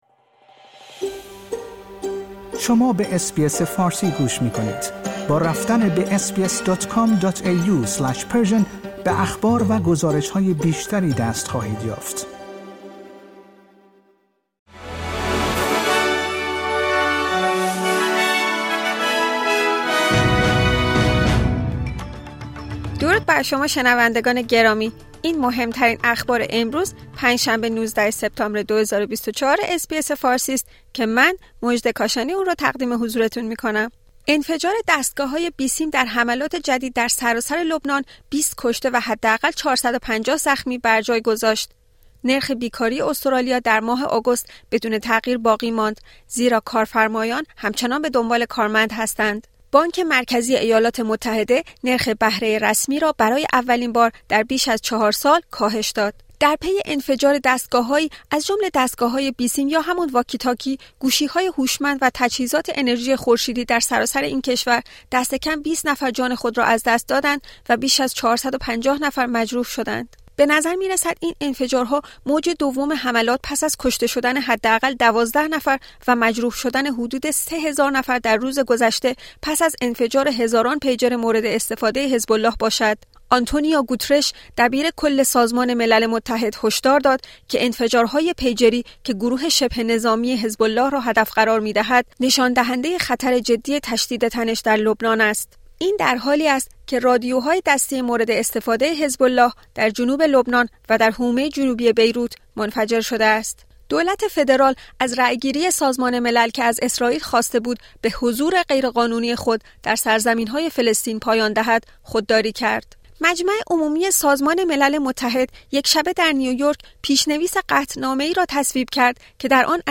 در این پادکست خبری مهمترین اخبار استرالیا در روز پنج شنبه ۱۹ سپتامبر ۲۰۲۴ ارائه شده است.